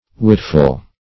witful - definition of witful - synonyms, pronunciation, spelling from Free Dictionary Search Result for " witful" : The Collaborative International Dictionary of English v.0.48: Witful \Wit"ful\, a. Wise; sensible.